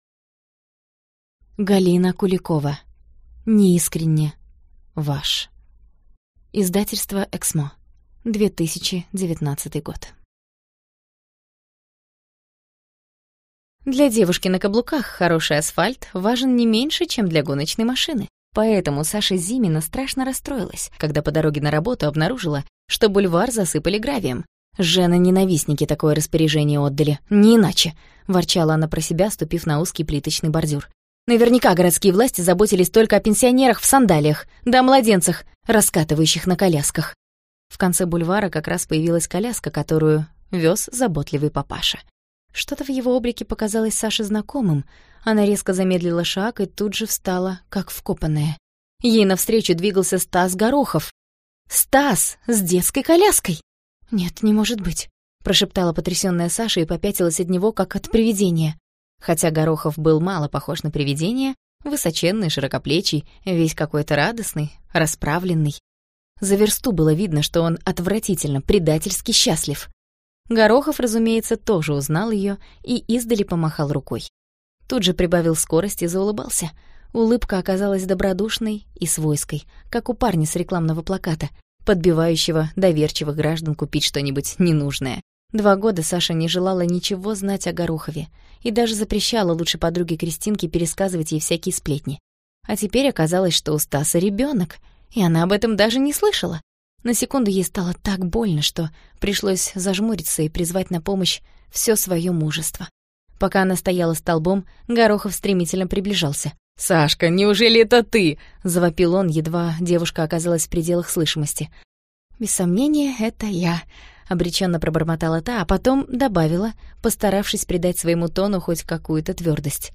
Аудиокнига Неискренне ваш | Библиотека аудиокниг